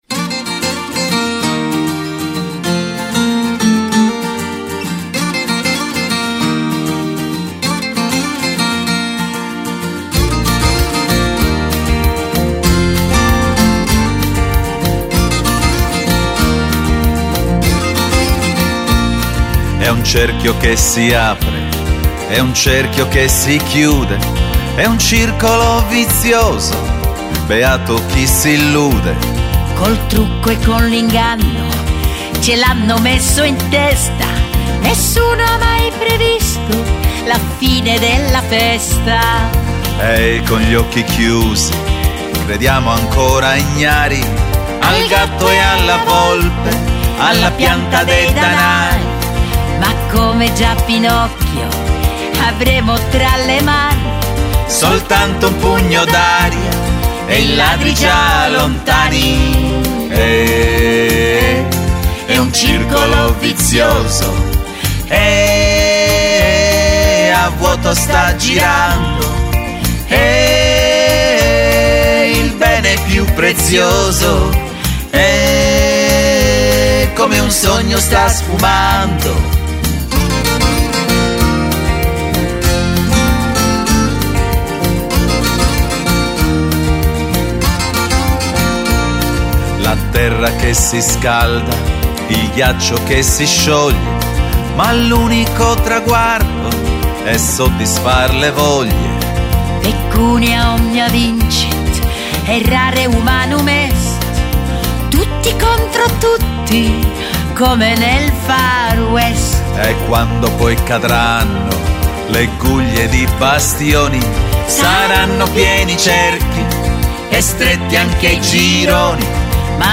Singer-songwriter / Cantautore.
who enriches these pensive songs